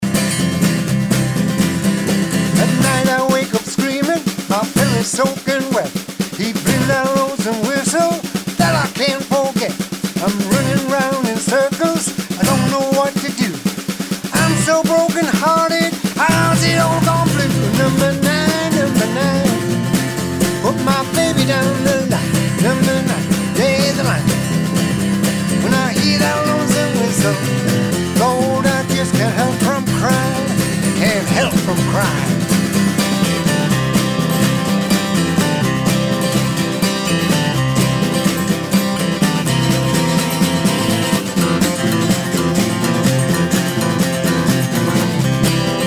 high energy Skiffle and Rock n' Roll